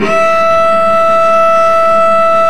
Index of /90_sSampleCDs/Roland - String Master Series/STR_Vc Marc&Harm/STR_Vc Harmonics